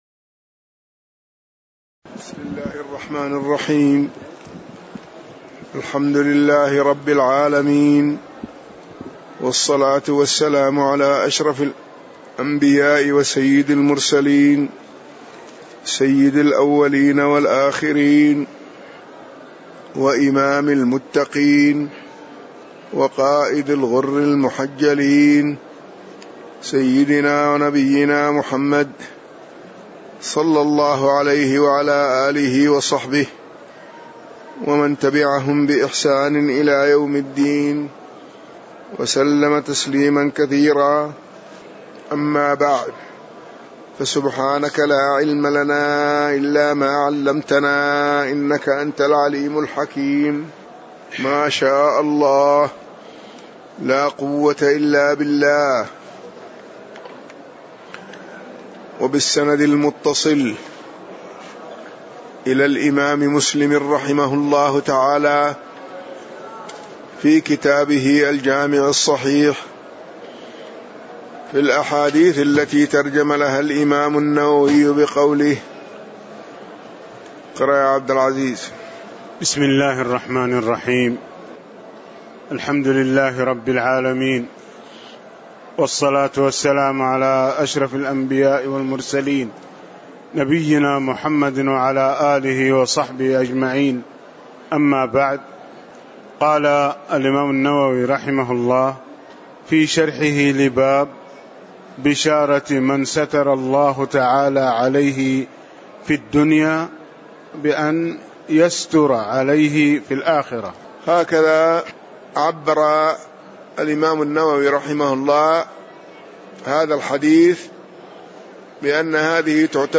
تاريخ النشر ١ صفر ١٤٣٨ هـ المكان: المسجد النبوي الشيخ